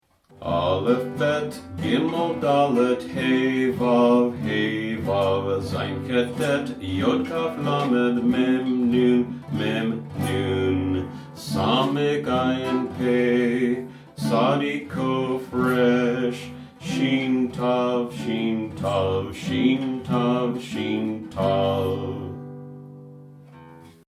When you listen to this 24-second ditty, you’ll be glad I chose a teaching rather than a singing career! Nevertheless, it should help you learn and remember the Hebrew alphabet.
Hebrew_Alphabet_Ditty.mp3